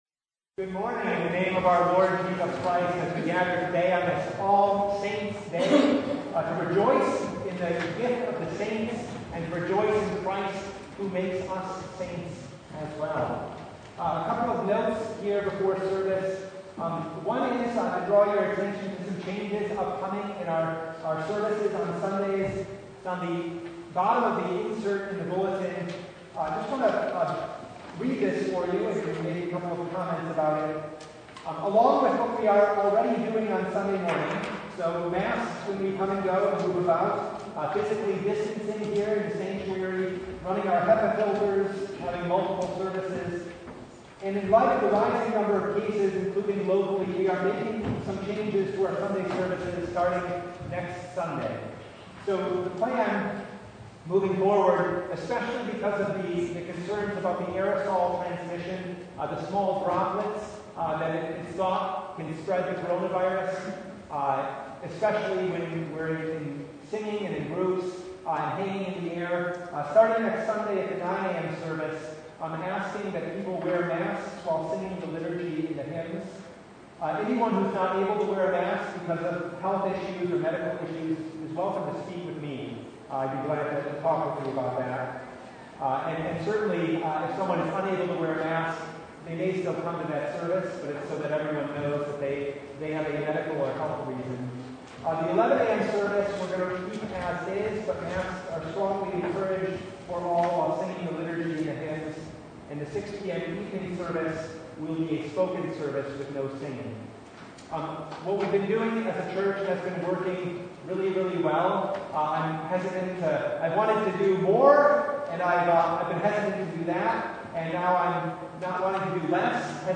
Download Files Notes Bulletin Topics: Full Service « Fake News, Fact Checking, and Jesus Saints?